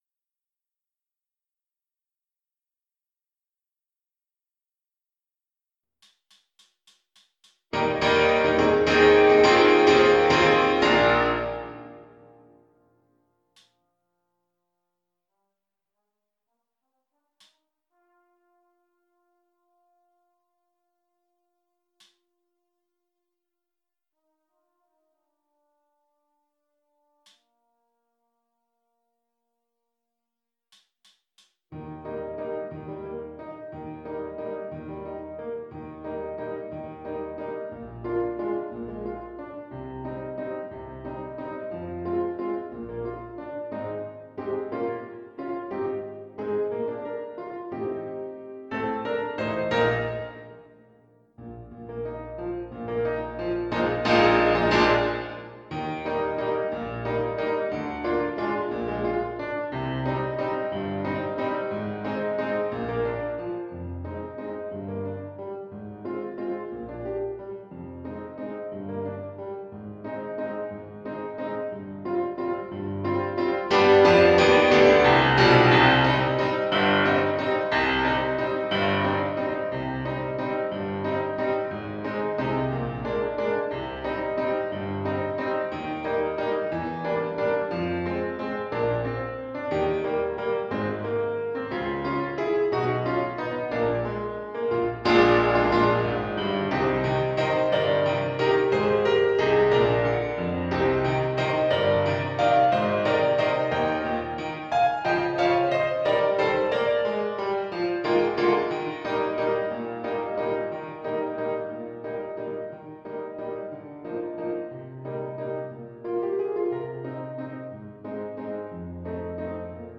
Alto Horn Piano